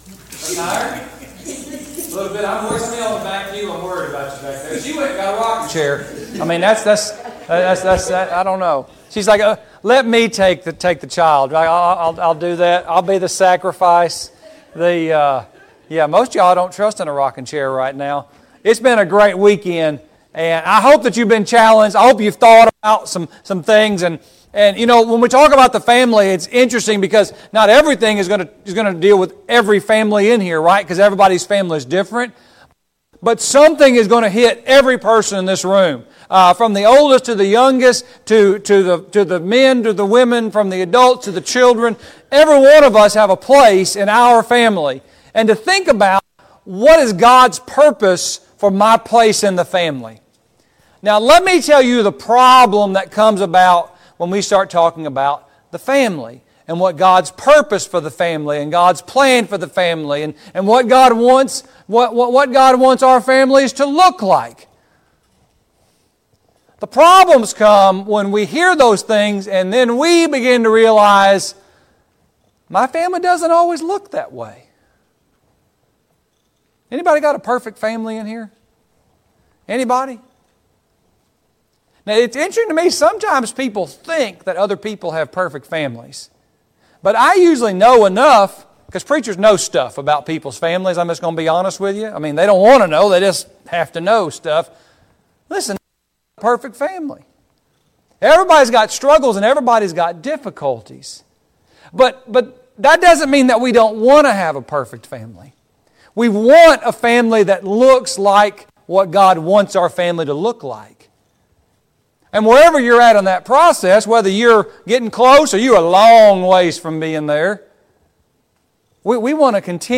Marriage and Family Training Service Type: Gospel Meeting « 4.